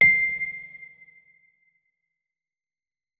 electric_piano
notes-72.ogg